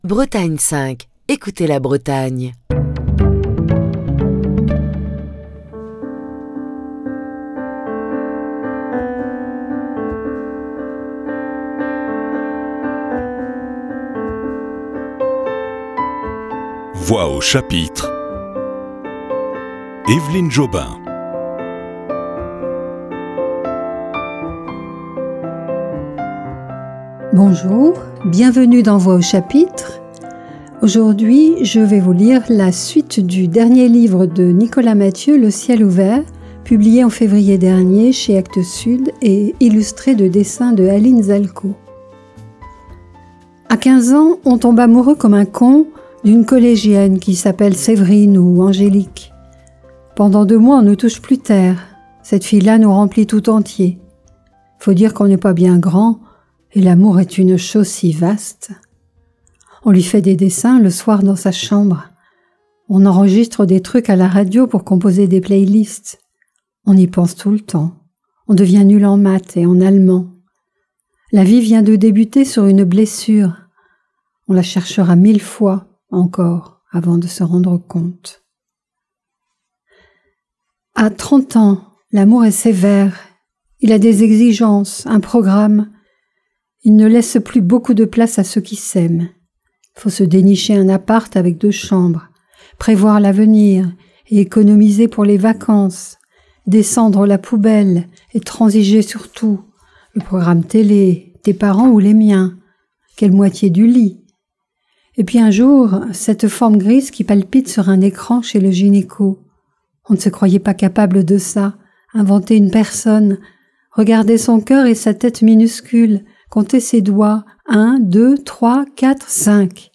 vous invite à découvrir quelques nouveaux extraits du roman de Nicolas Mathieu, "Le ciel ouvert", paru chez Actes Sud en février 2024.